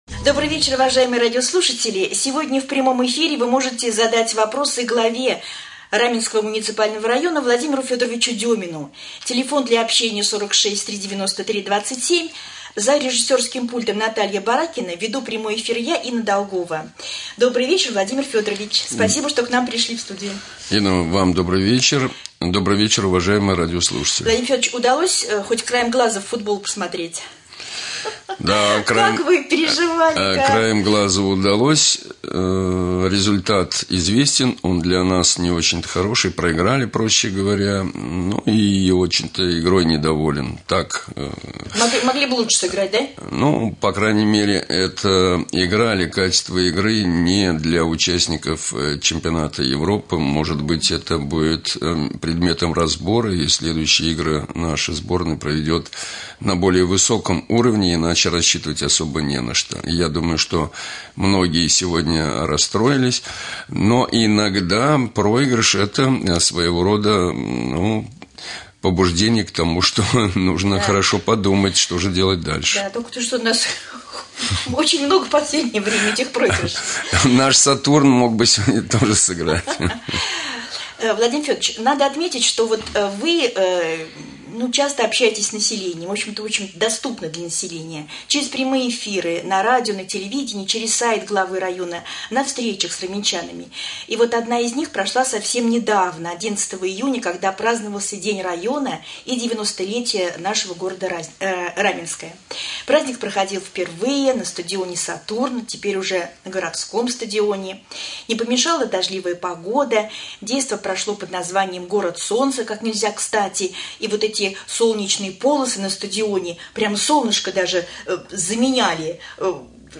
Владимир Демин ответил на вопросы жителей в прямом эфире Раменского радио